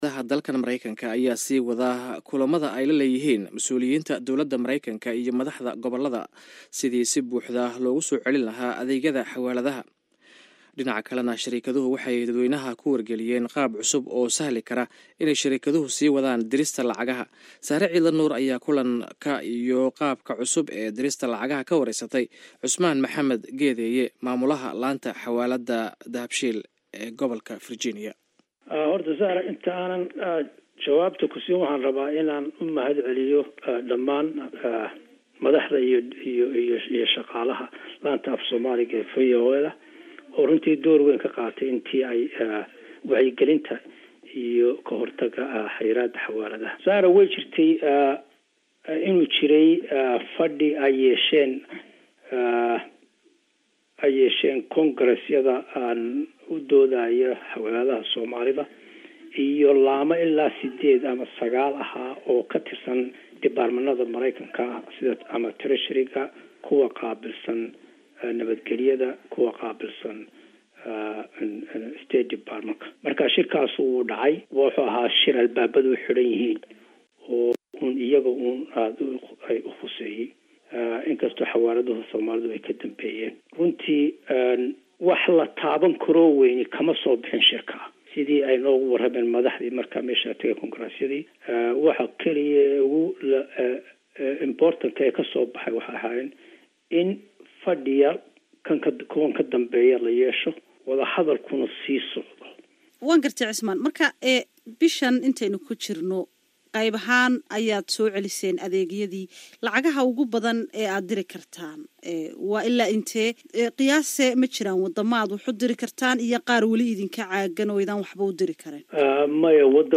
Hadaba warbixin kusaabsan sharciyada cusub ee lagu soo rogay Xxawaaladaha Soomaalida, iyo qaabka cusub ee dirista lacagaha halkaan ka dhagayso.